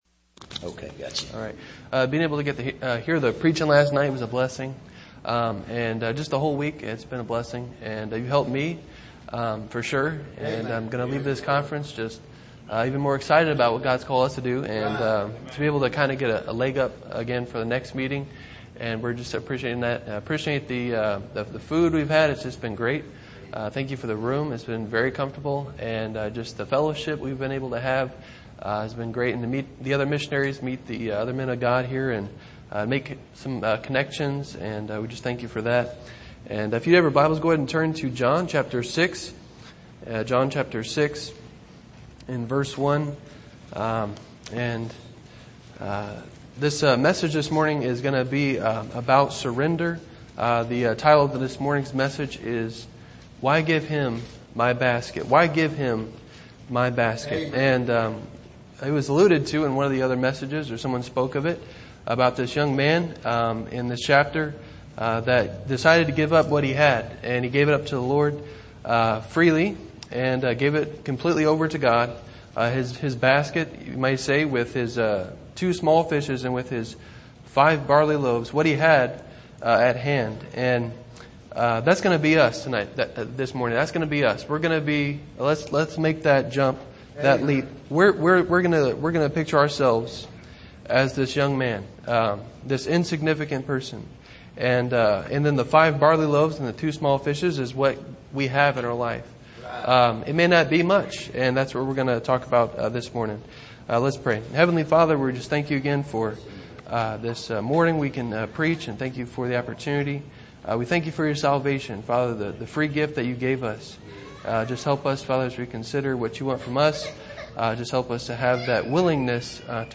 2021 Missions Conference Passage: John 6:1-13 Service: Missions Conference Why Give Him My Basket?